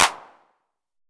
909 CLAP.wav